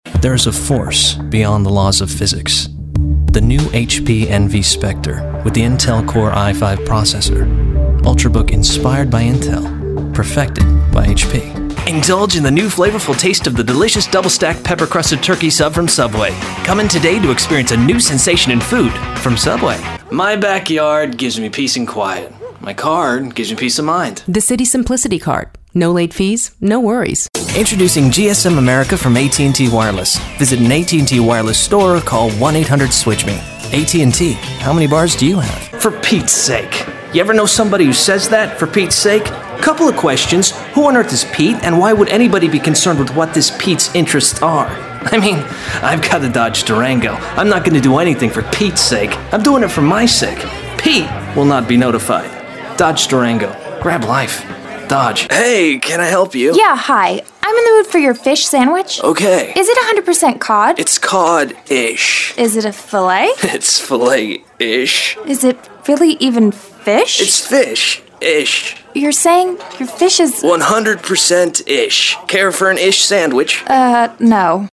Conversational, Honest, young guy next door. A voice you can trust.
englisch (us)
Sprechprobe: Werbung (Muttersprache):